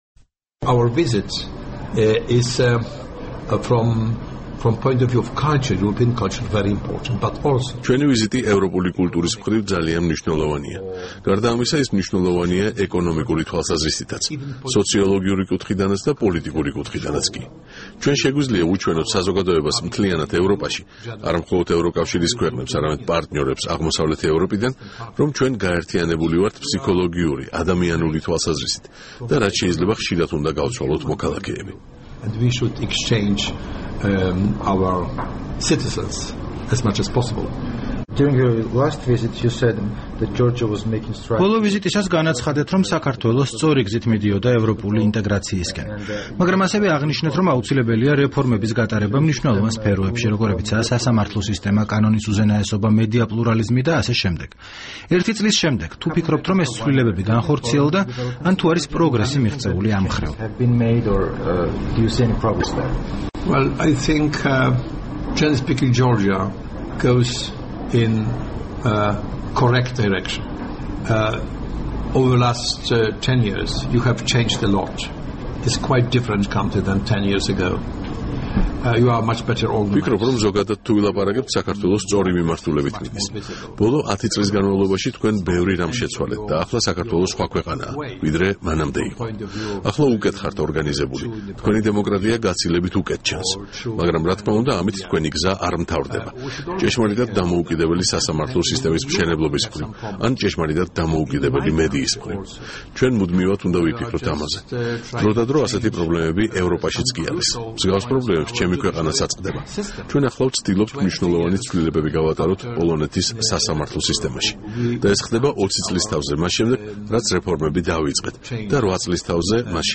საუბარი იეჟი ბუზეკთან